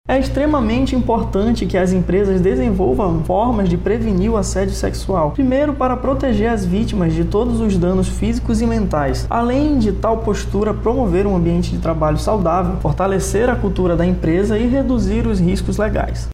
O defensor ressalta a importância das empresas abordarem esses temas em seus ambientes.